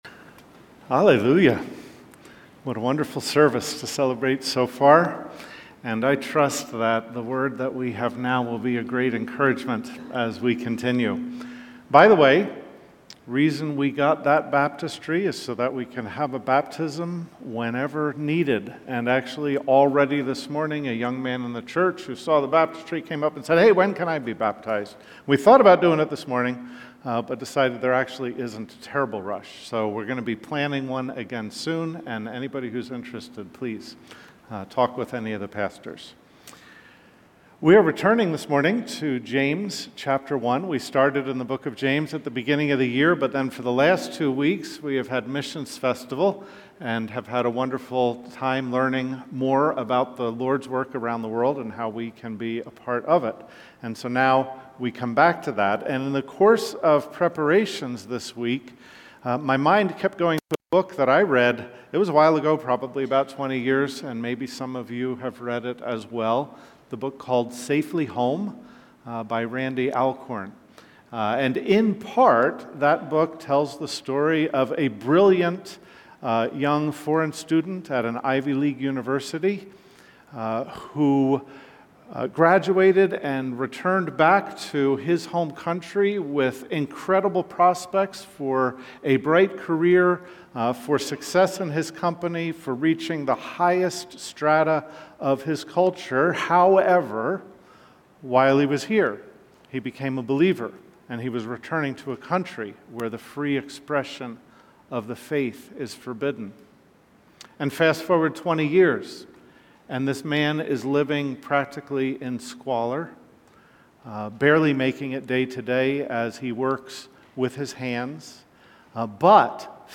Cary Alliance Church sermons